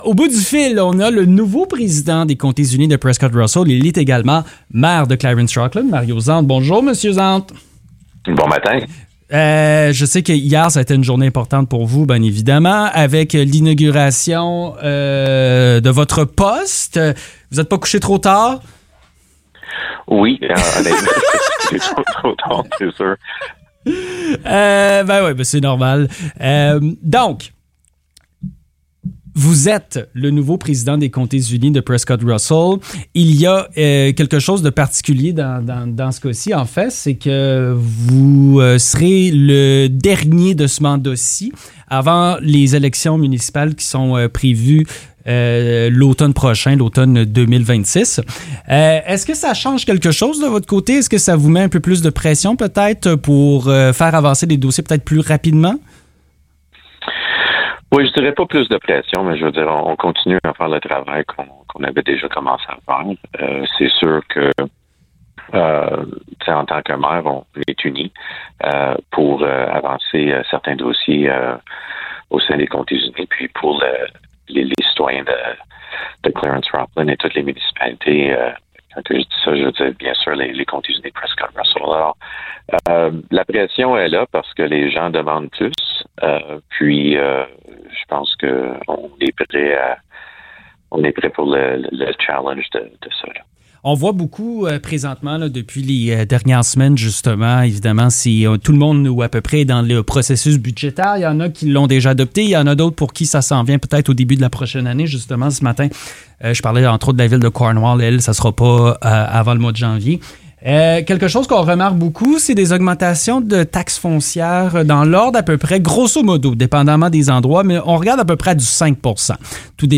Entrevue avec Mario Zanth – édition du 18 décembre 2025